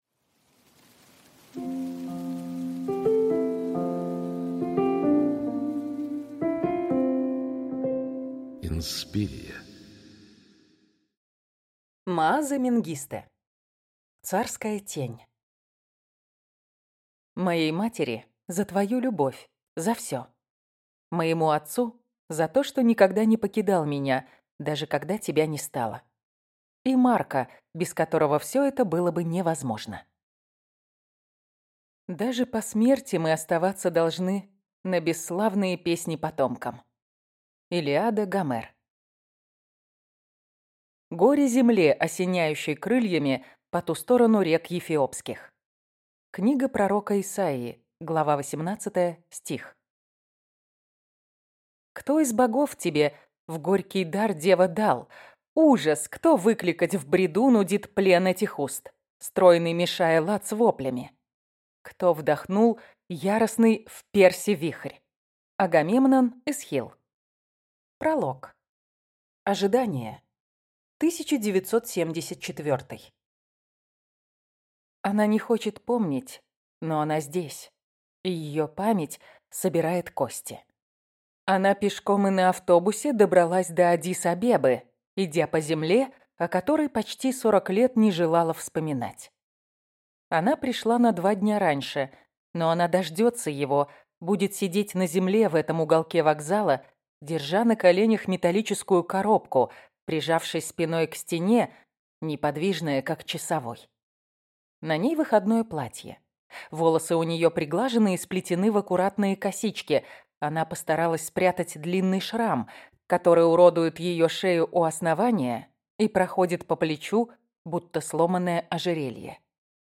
Аудиокнига Царская тень | Библиотека аудиокниг